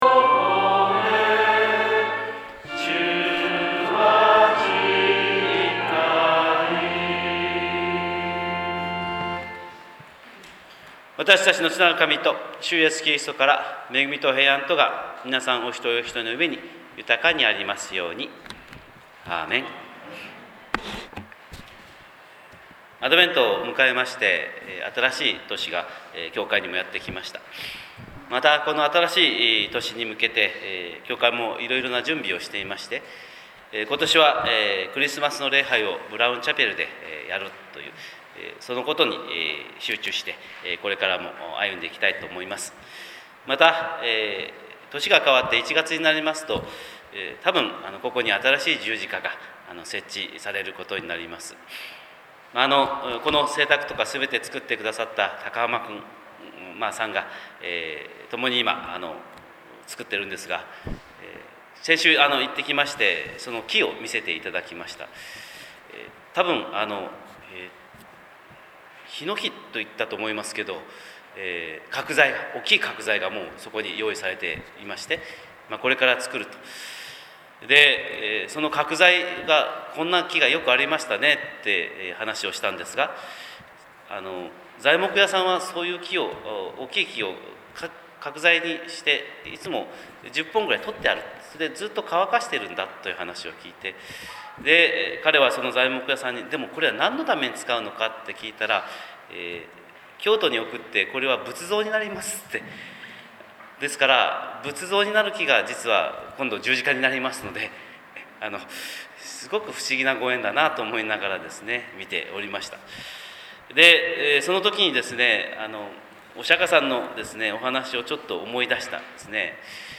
神様の色鉛筆（音声説教）
日本福音ルーテル教会（キリスト教ルター派）牧師の朝礼拝説教です！
朝礼拝131202